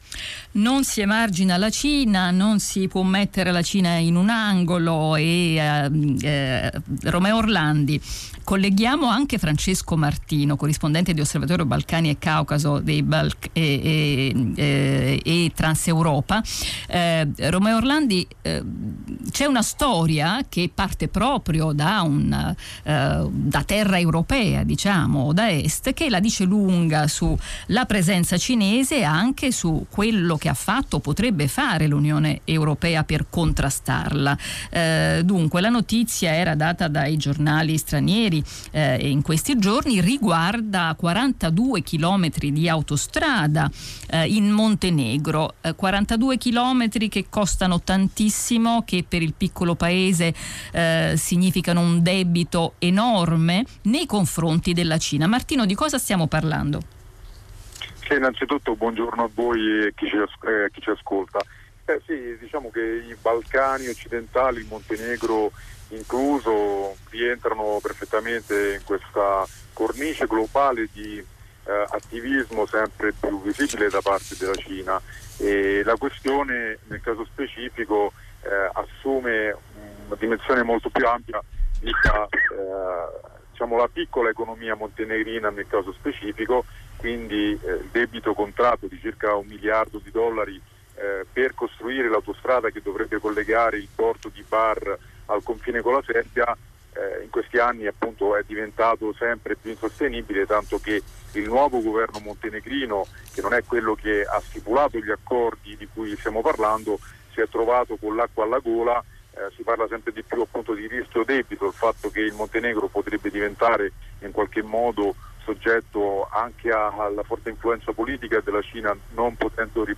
nella trasmissione "Mondo" di Radio Rai3 (9 giugno 2021)